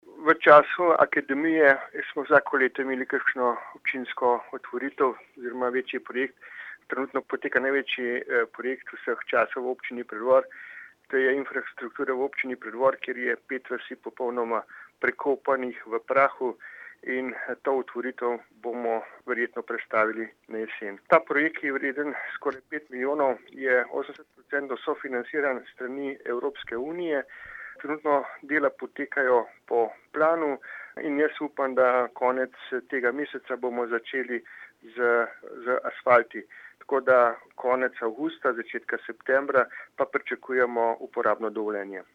44136_izjavazupanaobcinepreddvormiranazadnikarjaoobcinskiakademiji.mp3